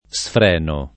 vai all'elenco alfabetico delle voci ingrandisci il carattere 100% rimpicciolisci il carattere stampa invia tramite posta elettronica codividi su Facebook sfrenare v.; sfreno [ S fr % no o S fr $ no ] — cfr. freno